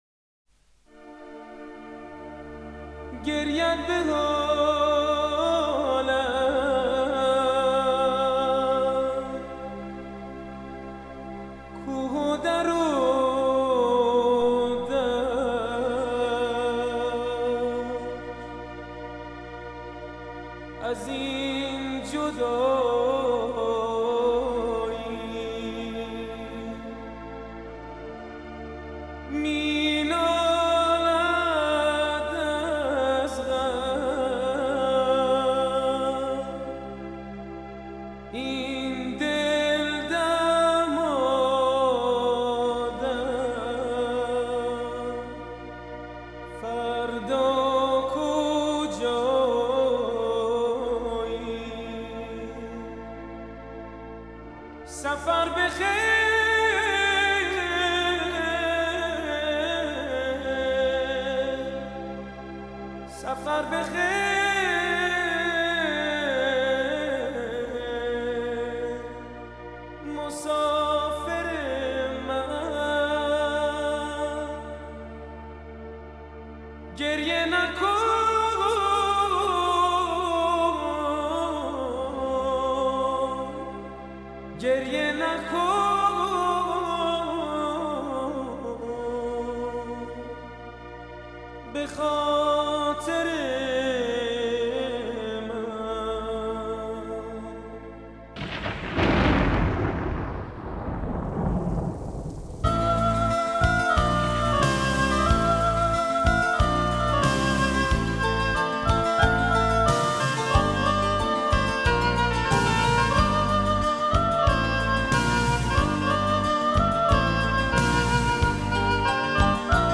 موسیقی پاپ فارسی
پاپ فارسی